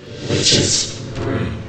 get_witch_broom.ogg